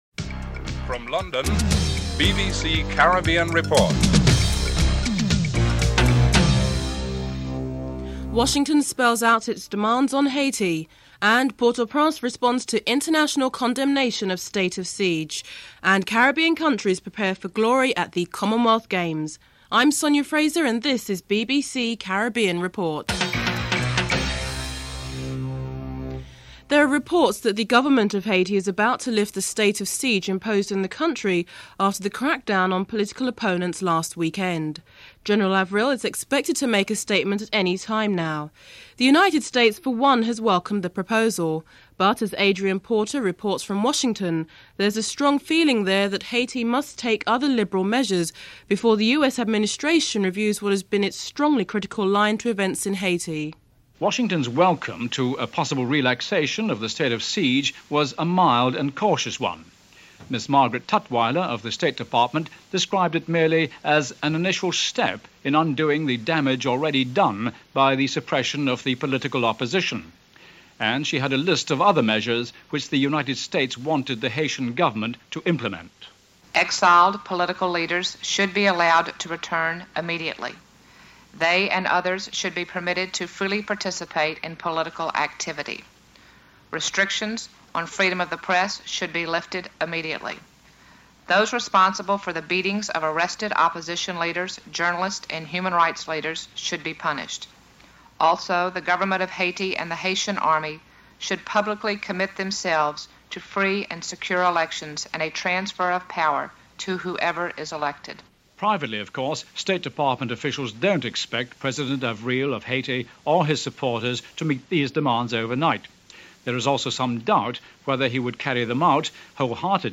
1. Headlines (00:00-00:25)
5. Round up of what the British press has been saying of interest to the Caribbean (08:33-11:36)